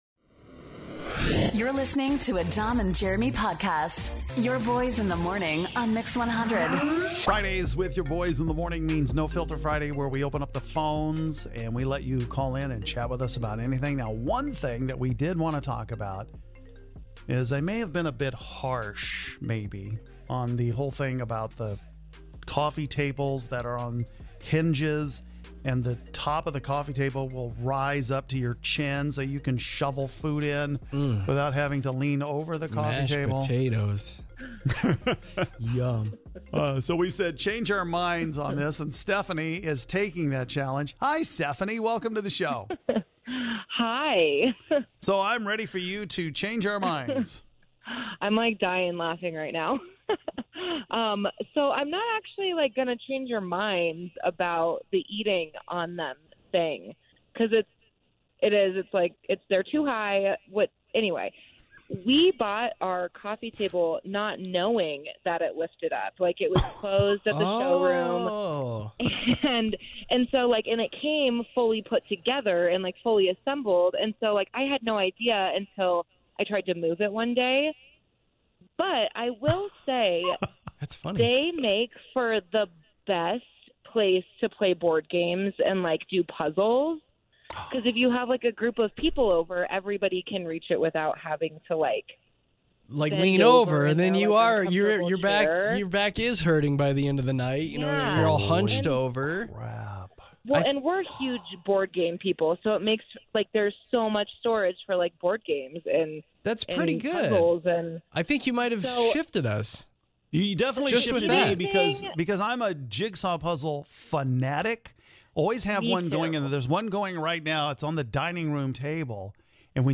We talk to YOU, our listeners about whatever is on your mind during No Filter Friday!